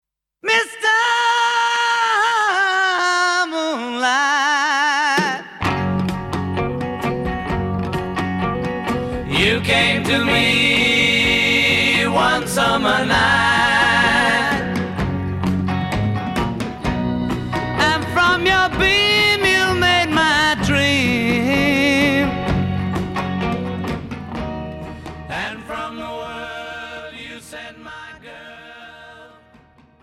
zpěv, kytara
zpěv, basová kytara, klávesy
zpěv, sólová kytara
perkuse